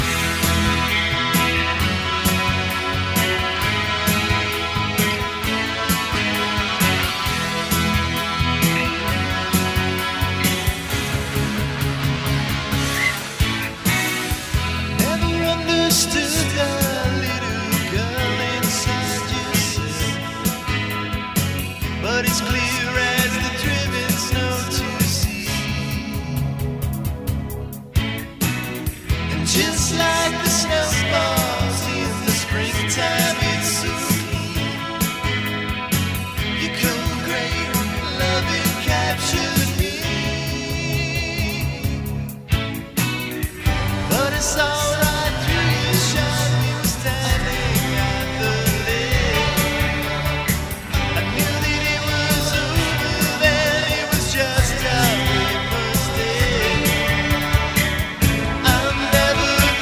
Every song here contains a strong memorable hook